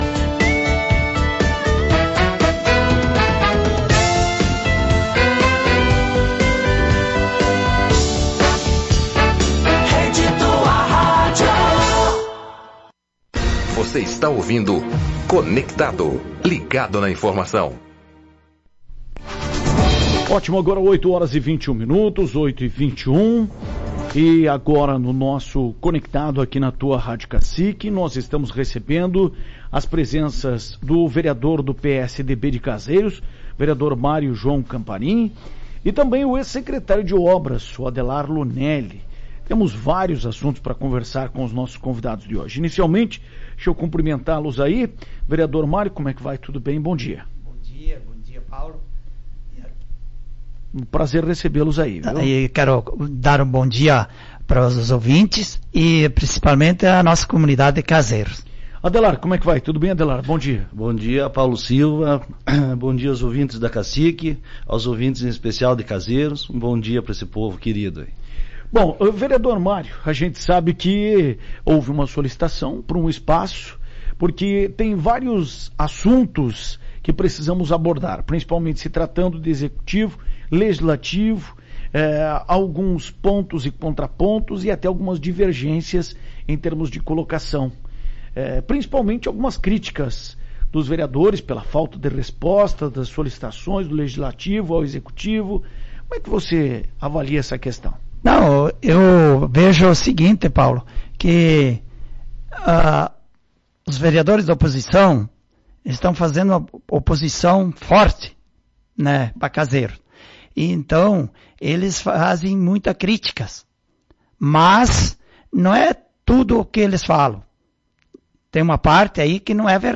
Em entrevista ao programa Conectado desta terça-feira, dia 09, ele informou que o motivo da saída se deu por motivos particulares, relacionados à família.
Além disso, o vereador Mário João Comparin, do PSDB, falou sobre a relação do executivo e do legislativo caseirense, destacando também alguns projetos do município.